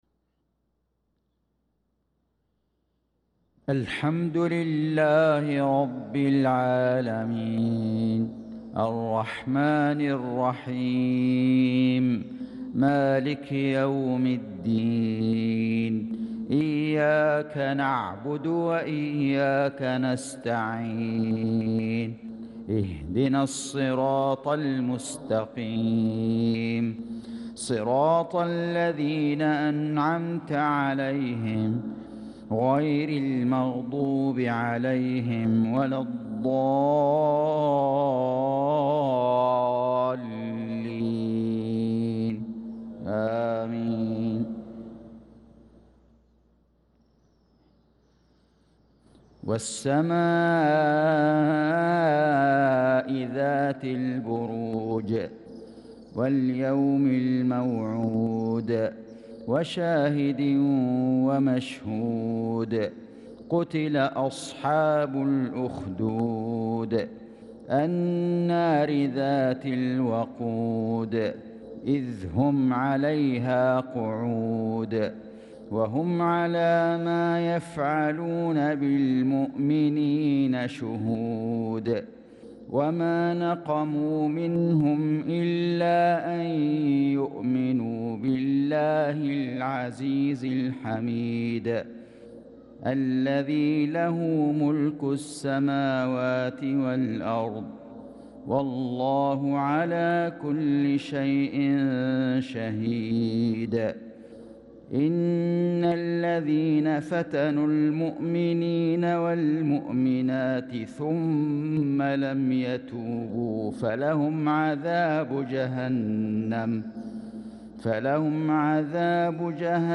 صلاة المغرب للقارئ فيصل غزاوي 21 ذو القعدة 1445 هـ
تِلَاوَات الْحَرَمَيْن .